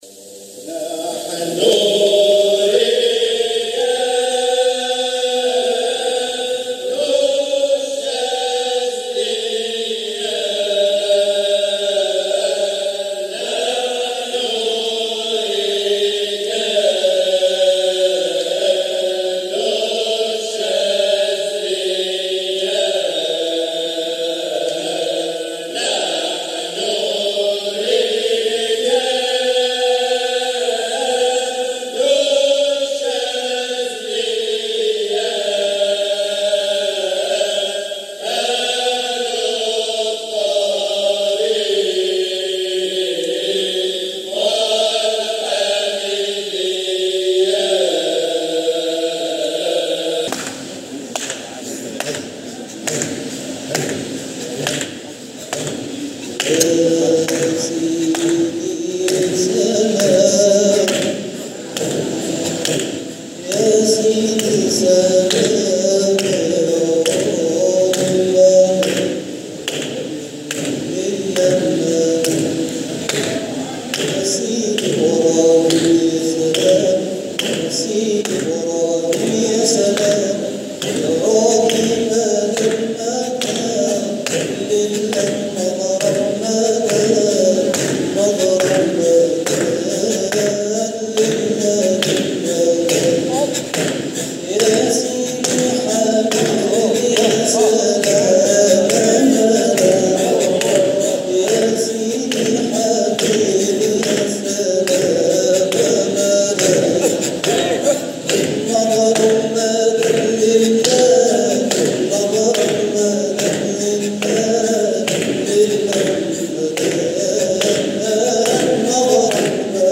جزء من حلقة ذكر بمسجد السلطان الحنفى ج٢- ١